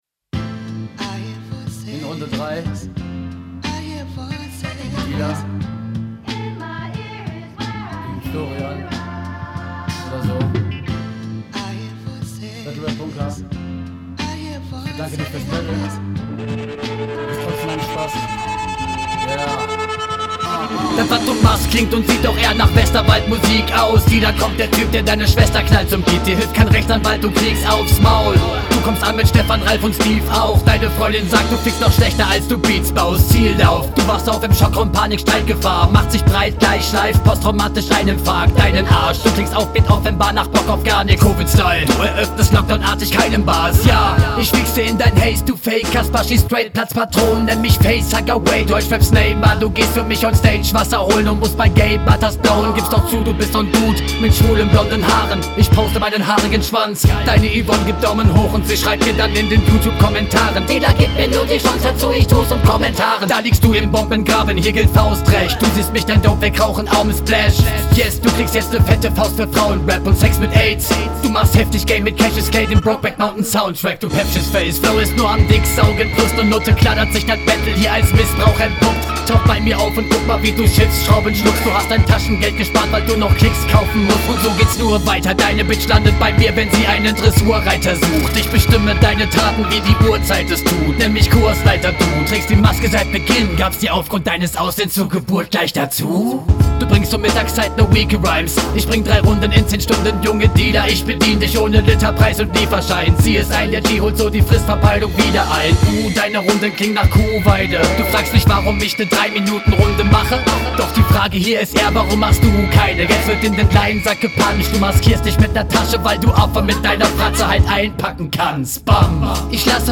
Flow, Technik und Betonung sitzt (mal wieder).